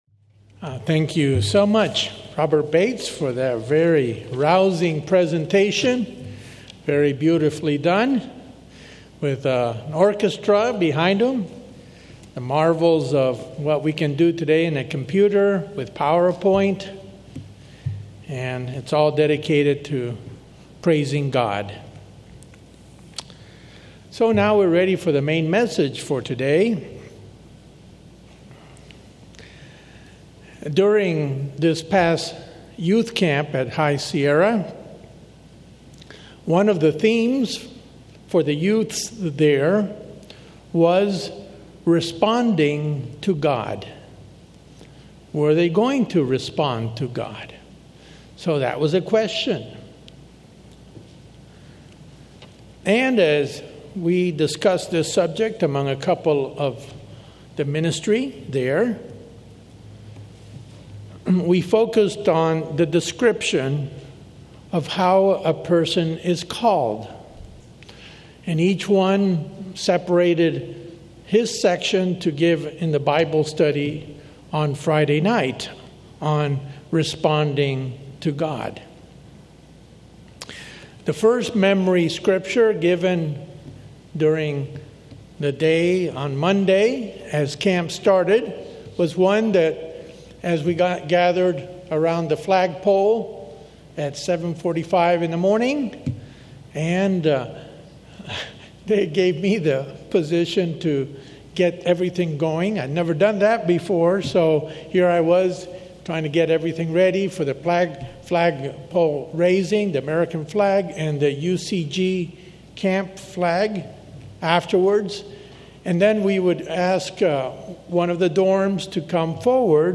In this sermon, you will hear how three of God's ministers responded to their being called by God.